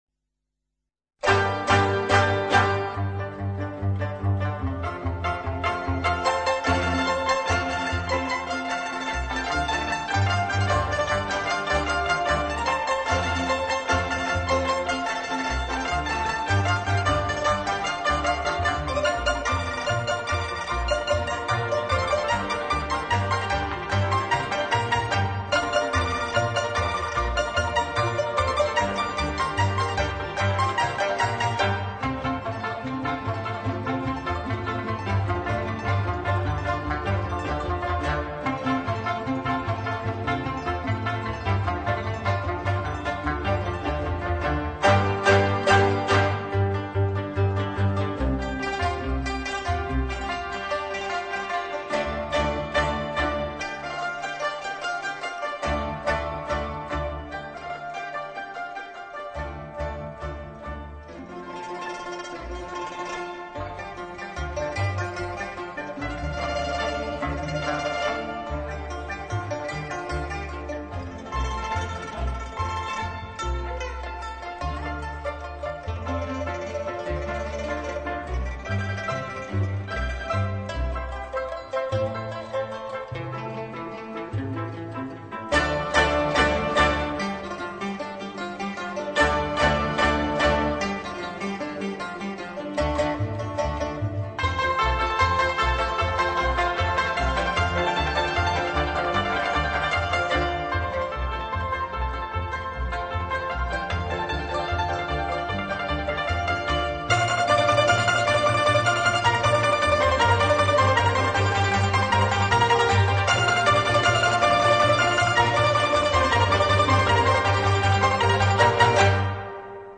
弹拨乐合奏